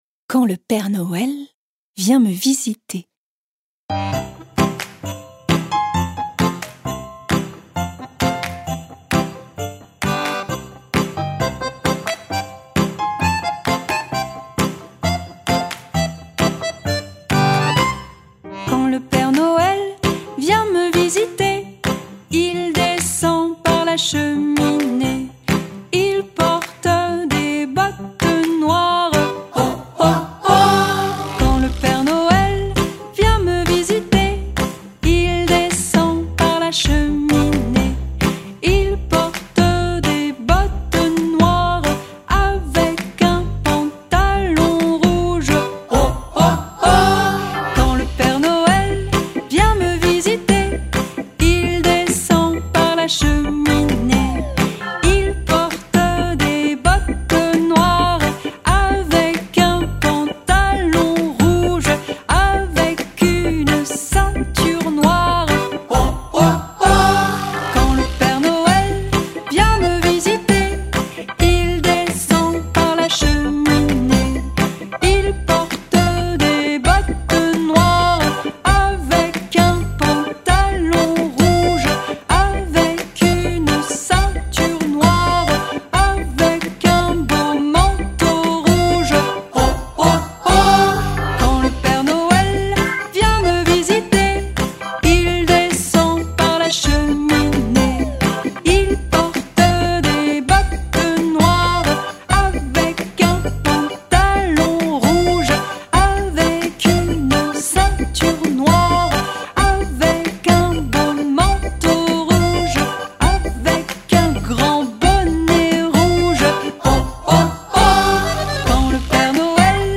Genre : Kids.